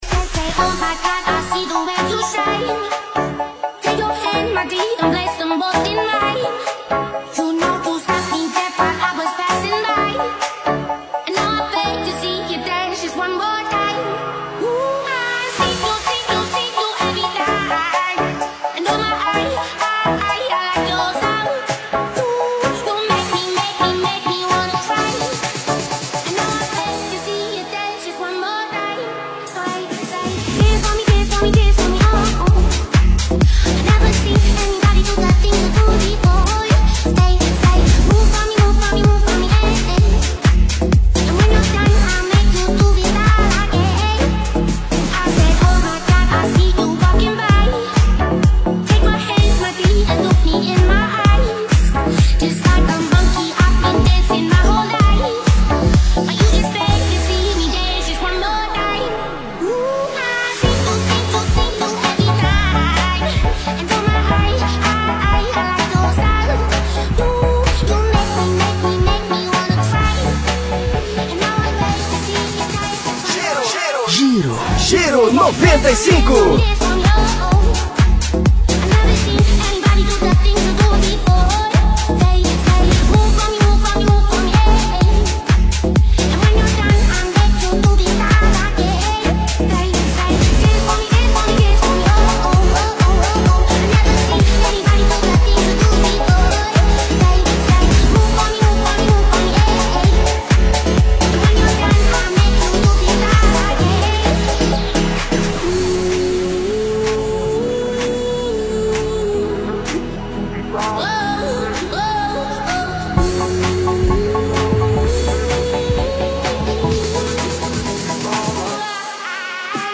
Estilo(s): Dance Deep-House